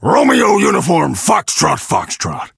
ruff_ulti_vo_06.ogg